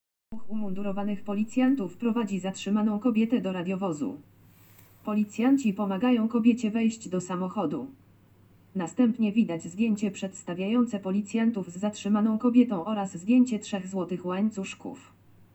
Audiodeskrypcja filmu Policjanci prowadzą zatrzymaną kobietę - plik mp3
AudiodeskrypcjafilmuPolicjanciprowadzazatrzymanakobiete.mp3